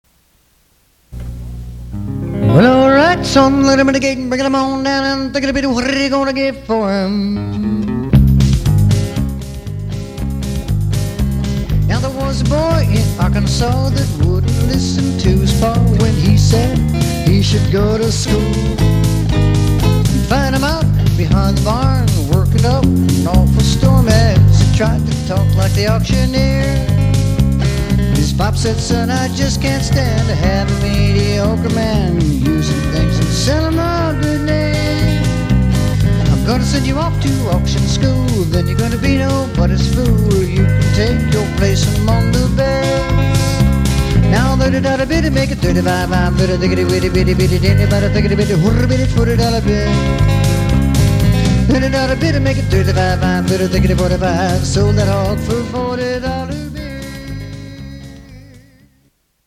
COUNTRY & WESTERN